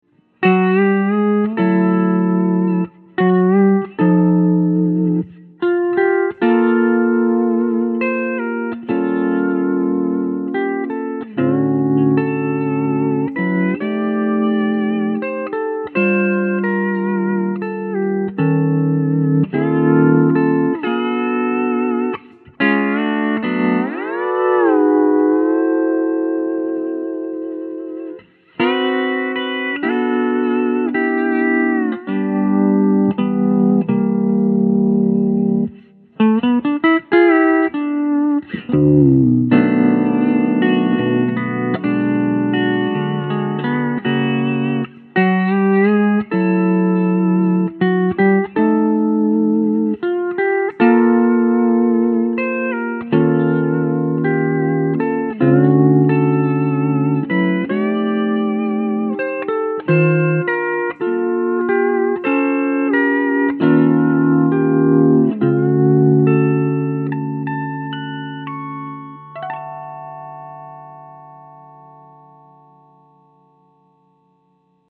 * is voiced perfectly for the cleanest, purest steel guitar sound I have ever heard
I just turned it on, stuck a mic in front of it and recorded a few soundbytes -- here are some examples